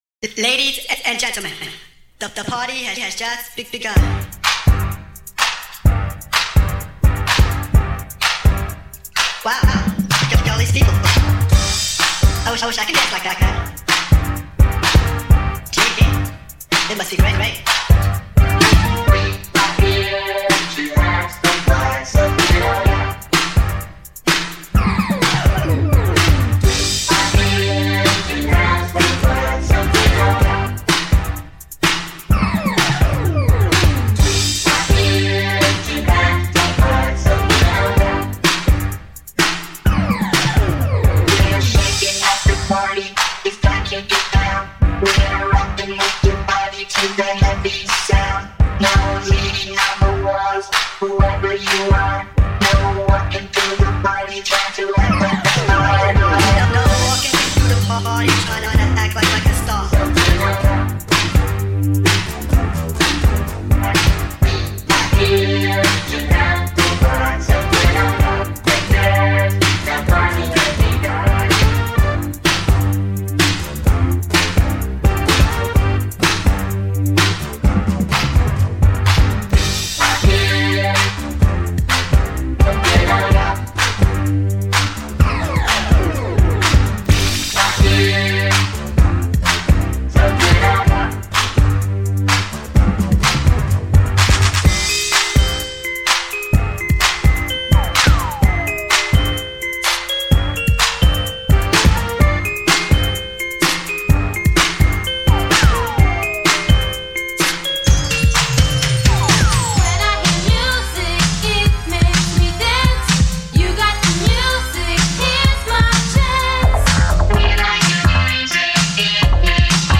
Uptempo Mix